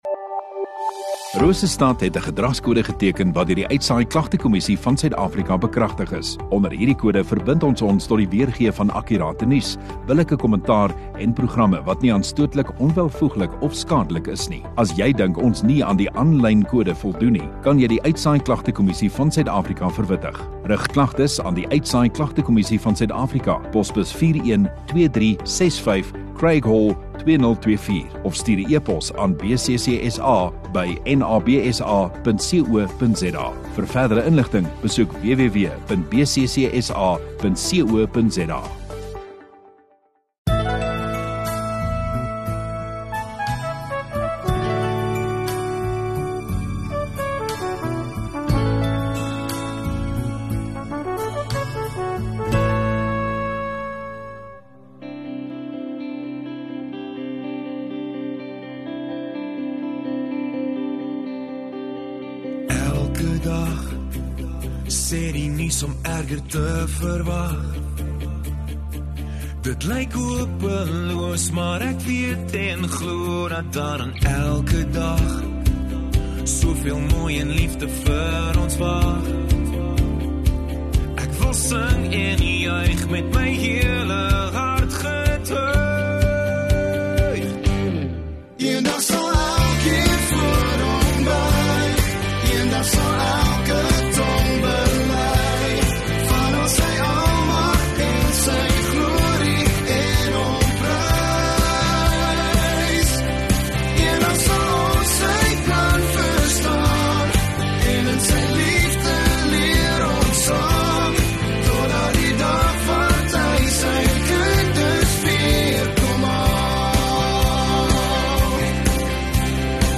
13 Sep Vrydag Oggenddiens